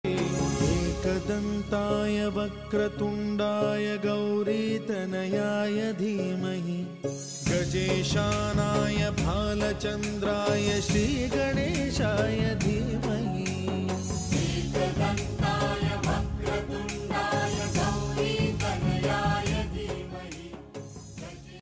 sacred chant